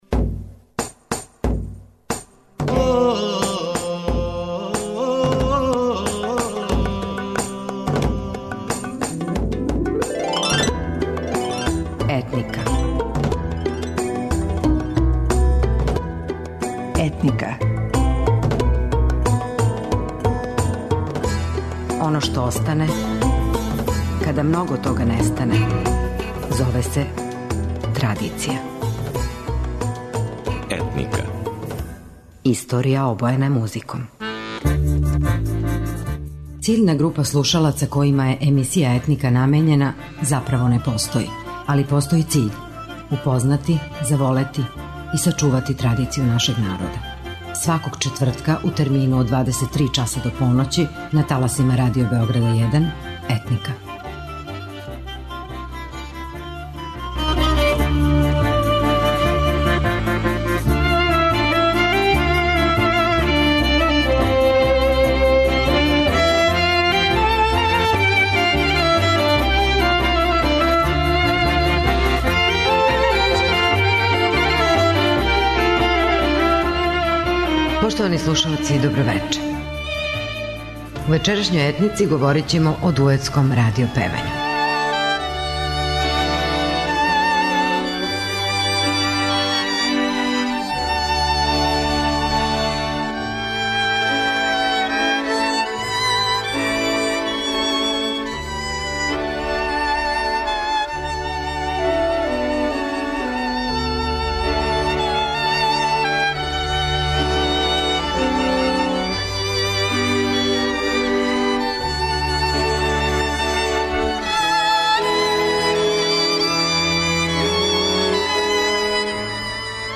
После 1945. године, појављају се квалитетни дуети, чије песме, забележене као трајне снимке и данас емитујемо.